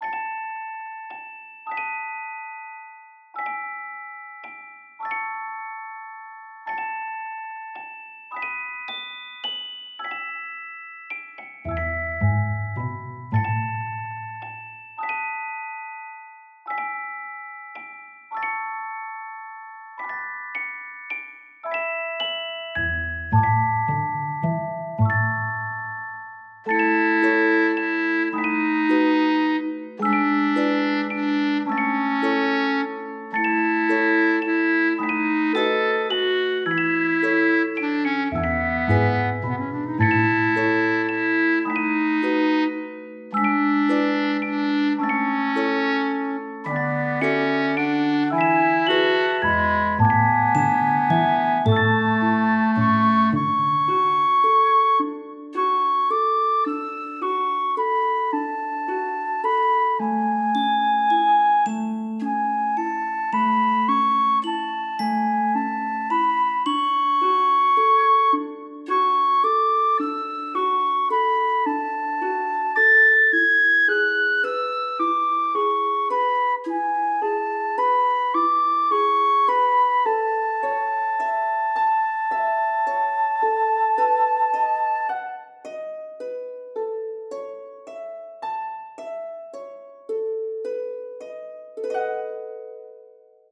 フリーBGM素材- 子守唄みたいなかんじ。